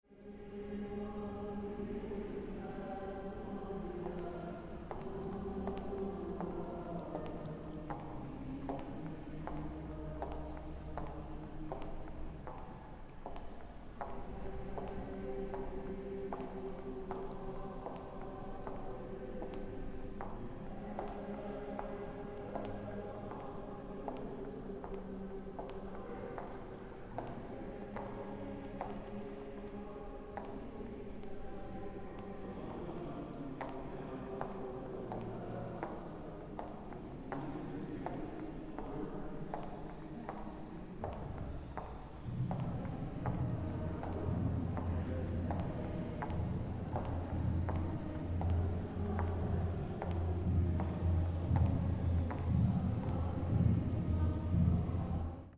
Corridor sounds at ITU
İstanbul Teknik Üniversitesi,
Inside the stone, concrete and marble give the hall corridor an echoing charachter, the cool whites and greys add to the place an air of calmness.
Listen to the sounds me walking around with my ‘clip-clops’ and the choir practising türküs at the lecture hall on the right: